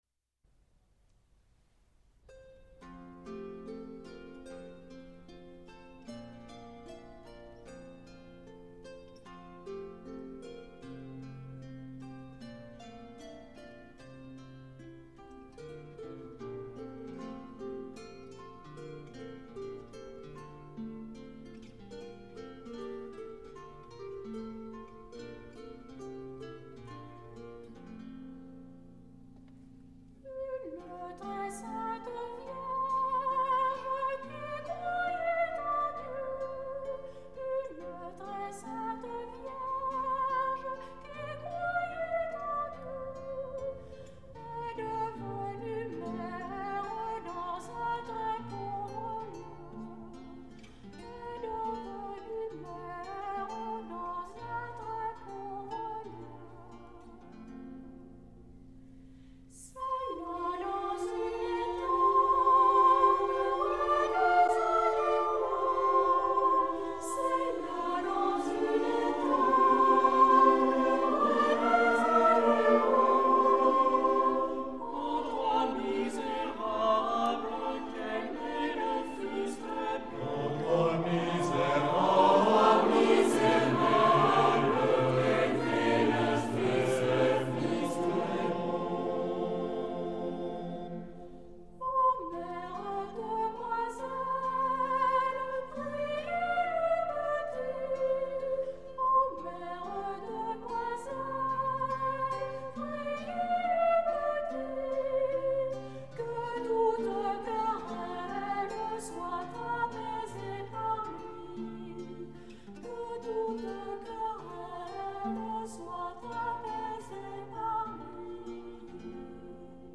pour choeur a cappella SATB